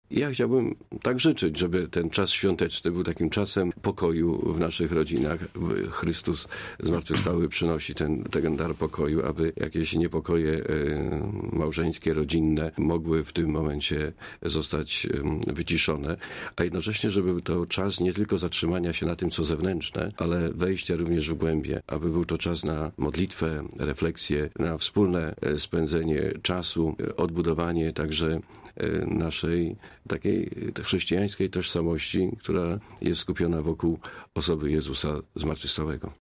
Biskup diecezjalny Tadeusz Lityński za pośrednictwem naszego radia złożył życzenia wszystkim wiernym.